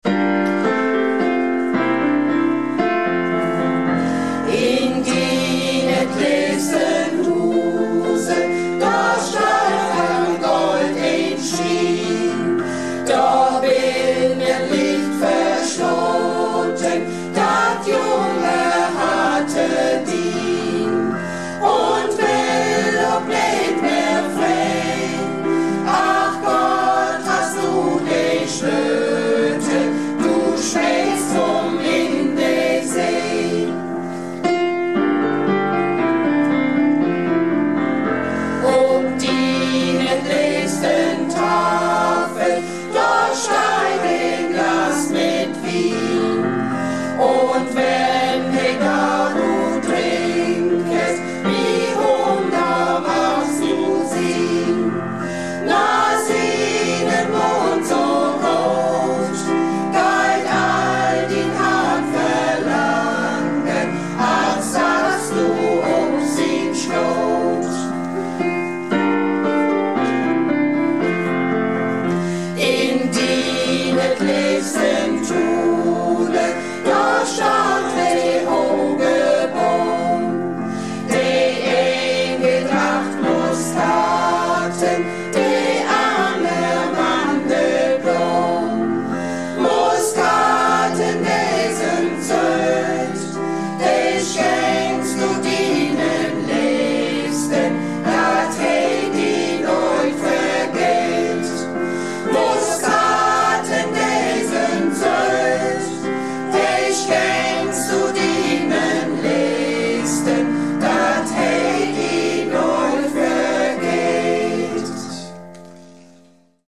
Malle Diven - Probe am 10.01.18